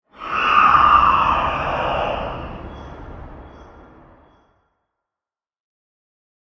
Minecraft Version Minecraft Version 1.21.5 Latest Release | Latest Snapshot 1.21.5 / assets / minecraft / sounds / block / sculk_shrieker / shriek3.ogg Compare With Compare With Latest Release | Latest Snapshot
shriek3.ogg